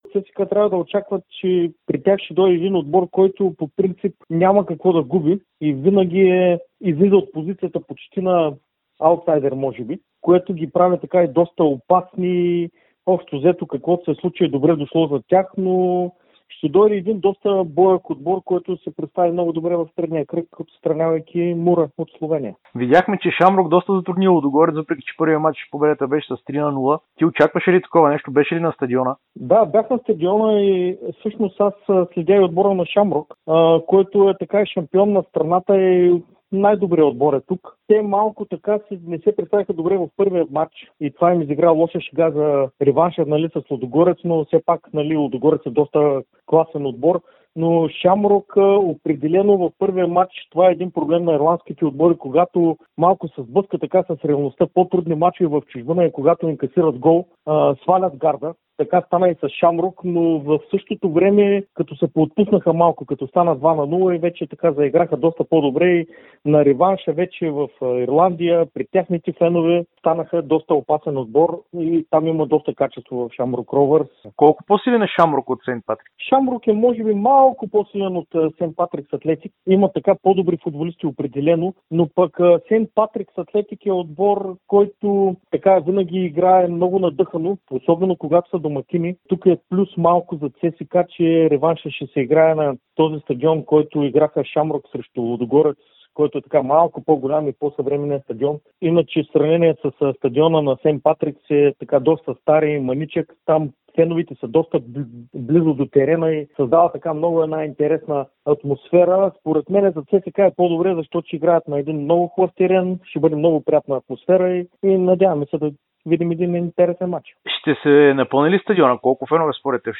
Той следи изкъсо футбола в Ирландия и се съгласи да отговори на въпросите на Дарик радио и dsport преди мачовете между ЦСКА и Сейнт Патркис.